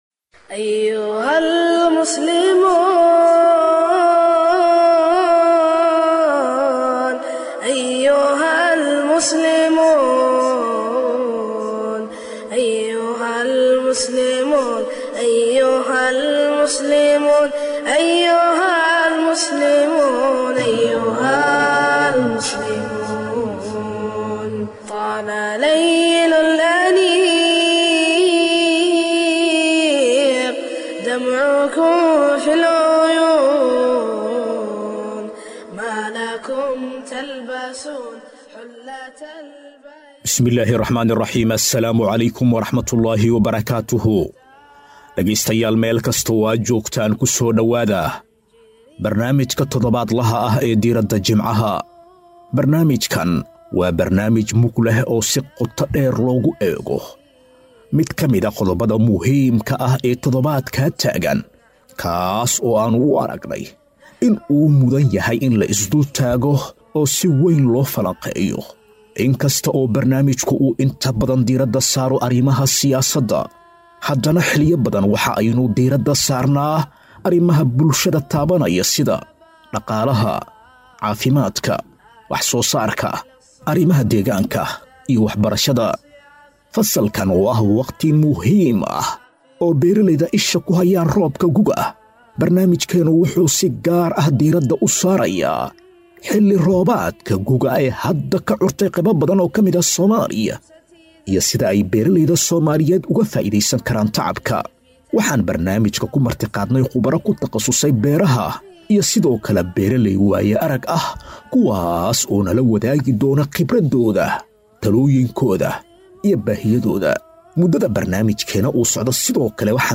Barnaamijka Diirada jimcaha waxaa maanta looga hadlay tacabka beeraha iyo sida looga faa’iideysan karo, waxaana ka qeyb gelaya khubaro ku xeeldheer tacbarashada beeraha.